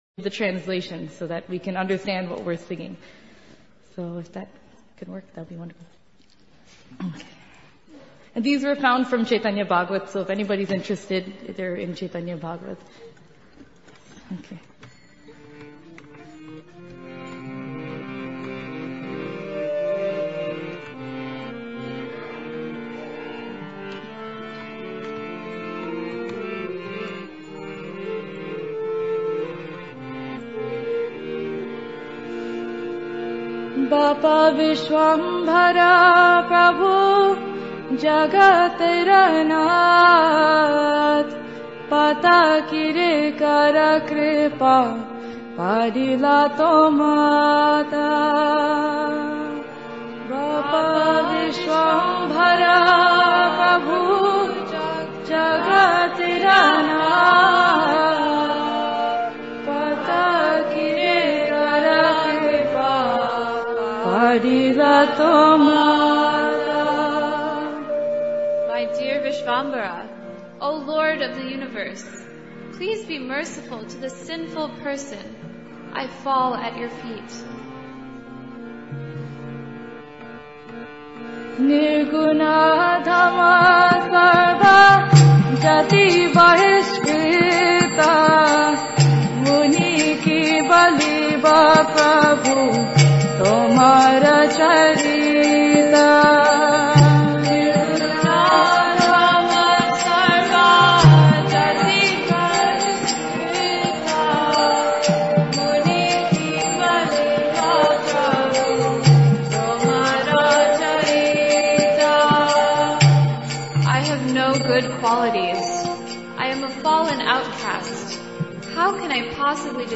New Year Retreat Chicago December 2017